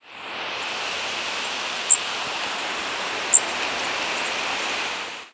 Blackpoll Warbler diurnal flight calls
Bird in flight.